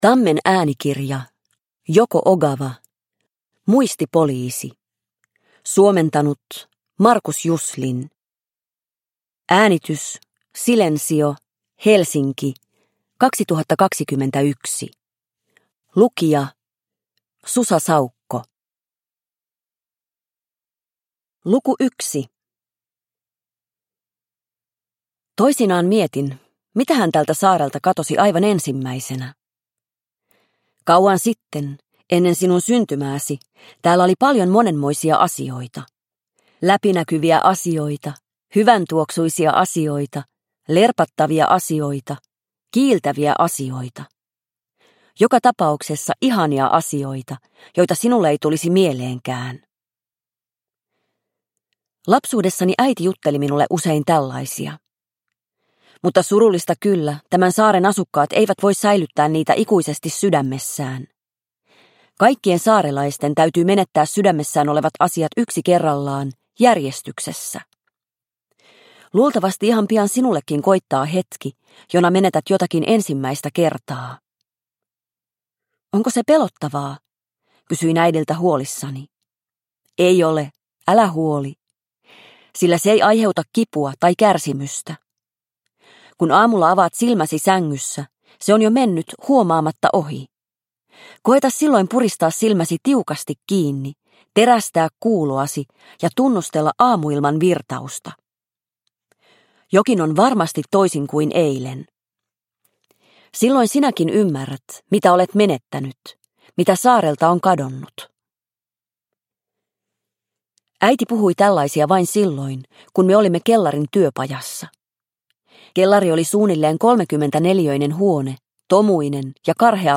Muistipoliisi – Ljudbok – Laddas ner